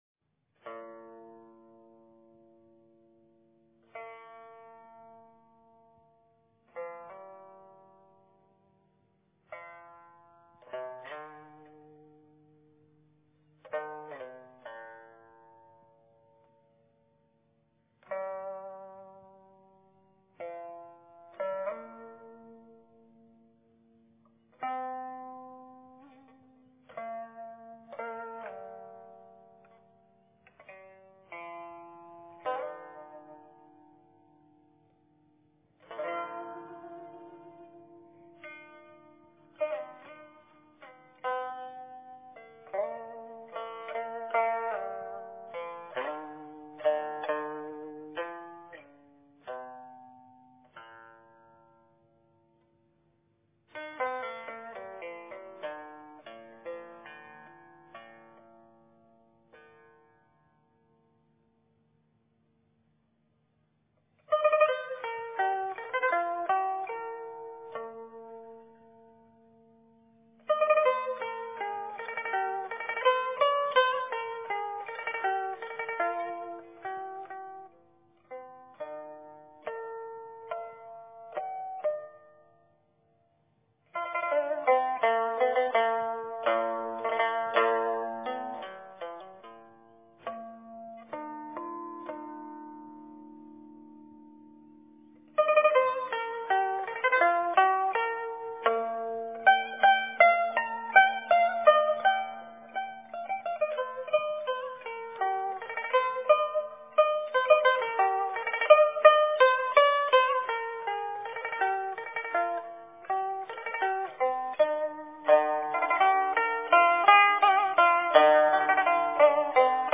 虚籁--刘天华 冥想 虚籁--刘天华 点我： 标签: 佛音 冥想 佛教音乐 返回列表 上一篇： 灵山梵呗--彭修文 下一篇： 东风又度-口琴--原来的我 相关文章 心境如如--黄友棣曲 心境如如--黄友棣曲...